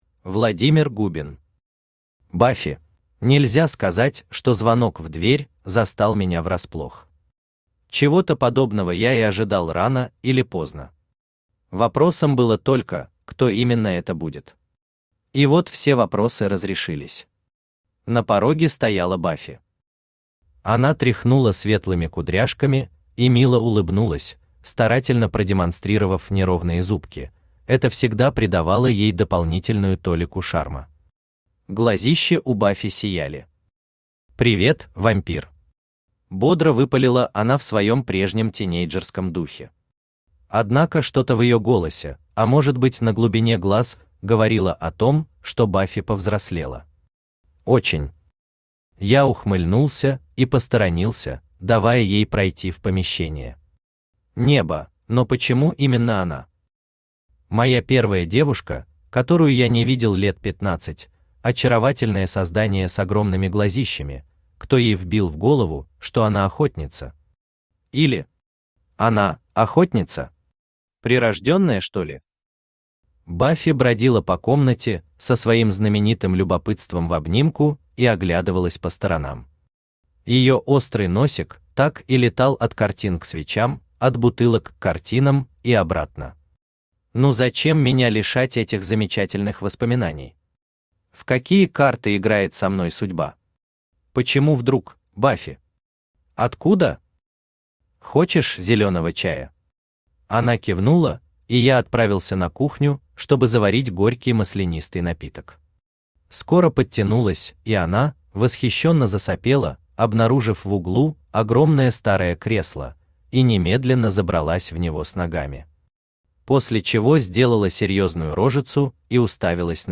Aудиокнига Баффи Автор Владимир Губин Читает аудиокнигу Авточтец ЛитРес.